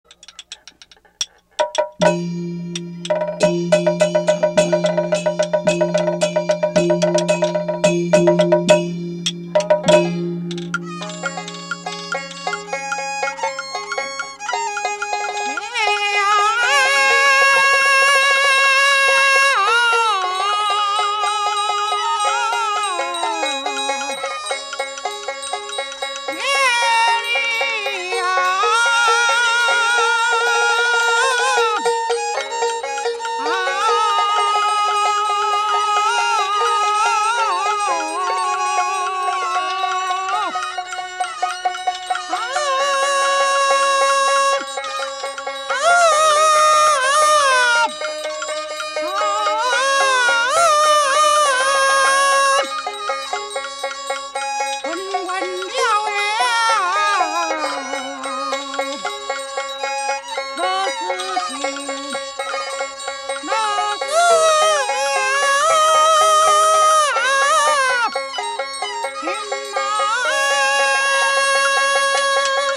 戲曲 - 羅成寫書選段2（倒板） | 新北市客家文化典藏資料庫